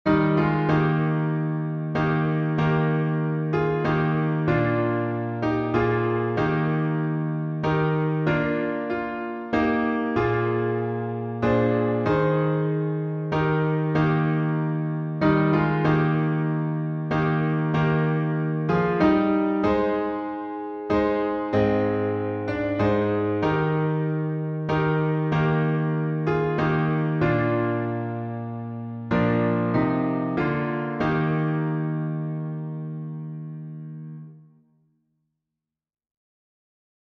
#3056: Just as I Am — E flat major | Mobile Hymns
Just_As_I_Am_Eflat.mp3